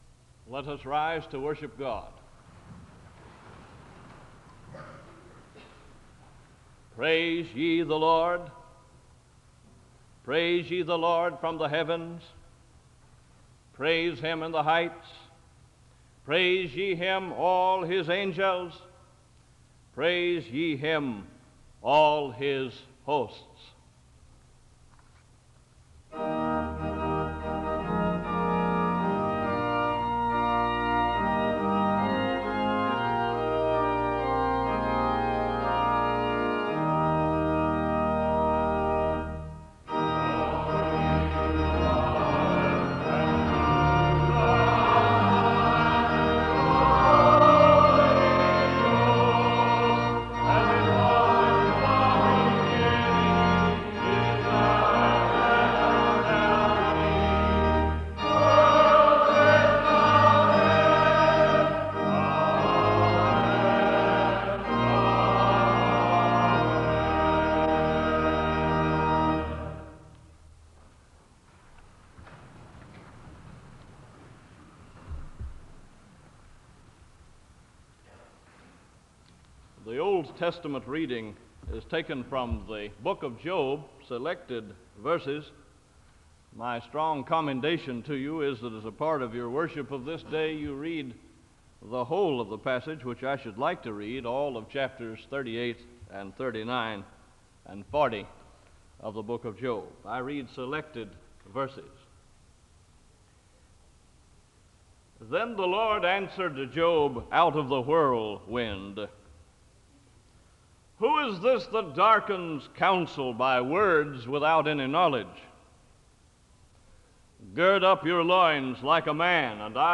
The service opens with a hymn of worship (00:00-01:14). The speaker reads from selected sections from the book of Job and the book of Revelation, and he gives a word of prayer (01:15-07:52). The choir leads in a song of worship (07:53-13:06).
The service ends with the singing of the Doxology and a benediction (25:56-27:04).
In Collection: SEBTS Chapel and Special Event Recordings